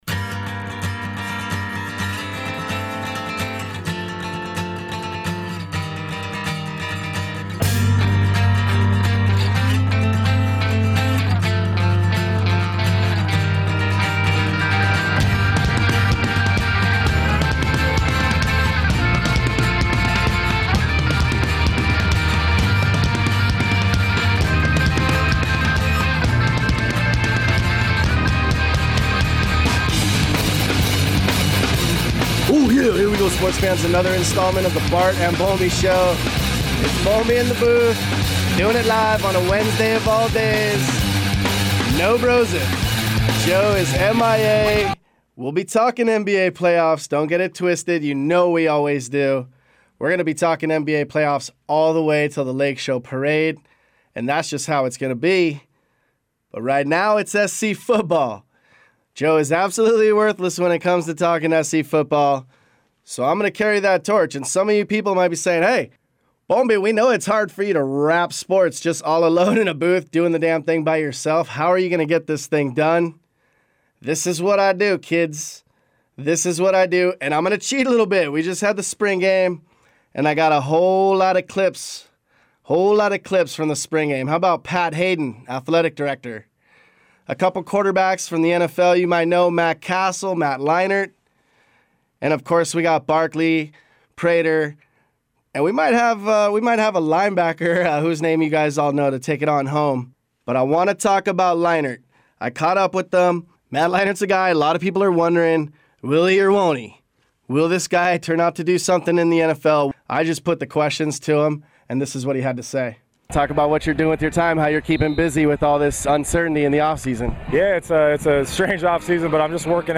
Leinart talks about his NFL odyssey and what he expects from himself, in the coming year.